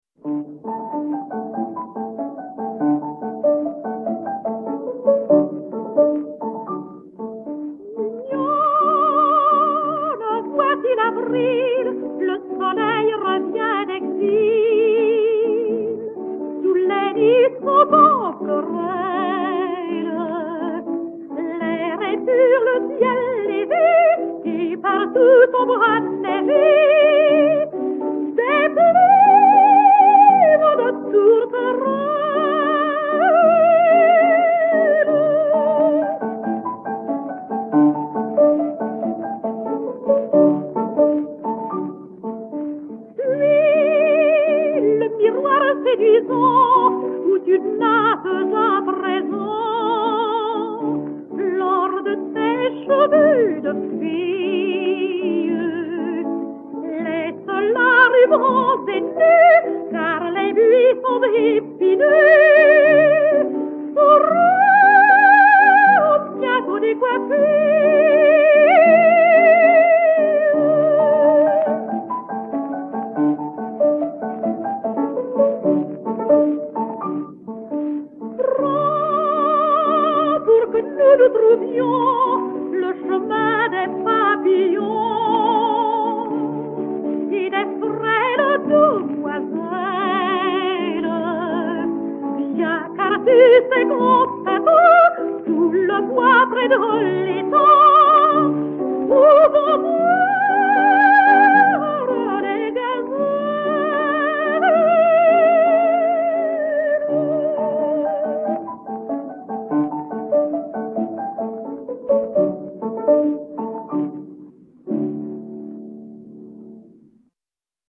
Ninon Vallin, soprano, avec piano
Vallin - Serenade du passant.mp3